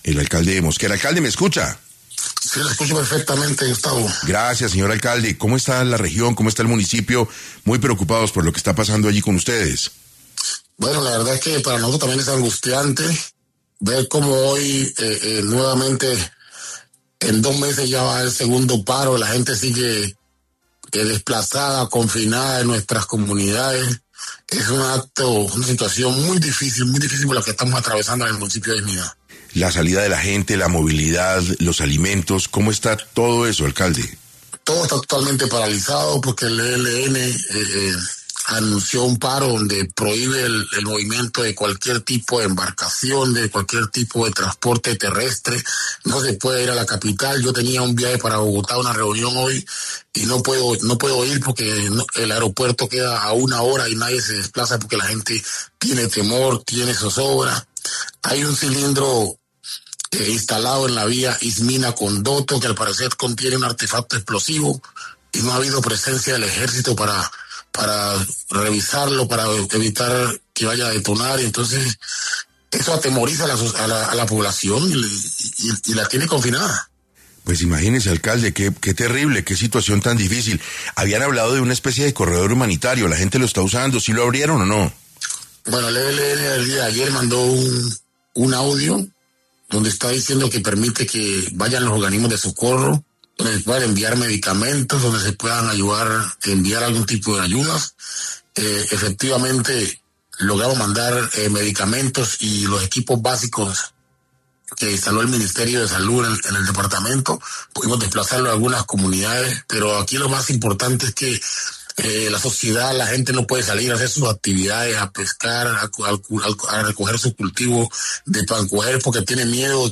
En 6AM de Caracol Radio estuvo Jaison Mosquera, alcalde de Istmina, Chocó, para hablar sobre cómo está el municipio ante el paro armado del ELN y cuántas familias han salido desplazadas y las que están confinadas.